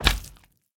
Minecraft Version Minecraft Version snapshot Latest Release | Latest Snapshot snapshot / assets / minecraft / sounds / mob / slime / big1.ogg Compare With Compare With Latest Release | Latest Snapshot